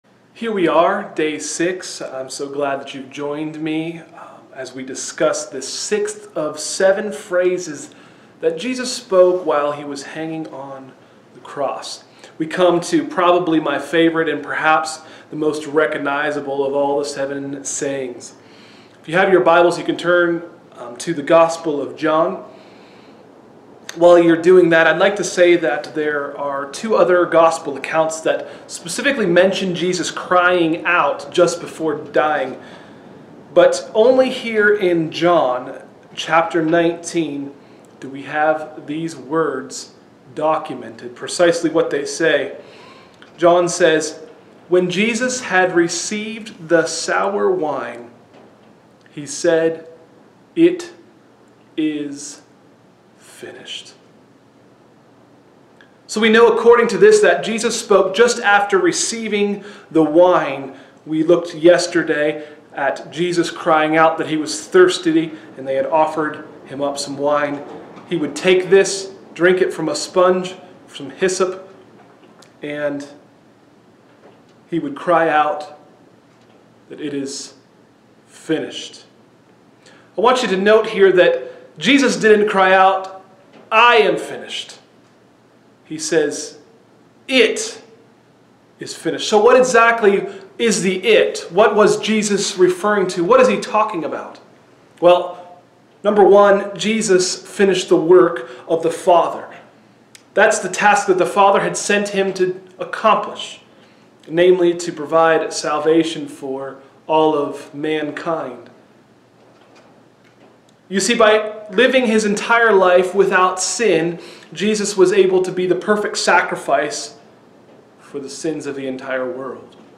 Sermons: “6th Word It Is Finished” – Tried Stone Christian Center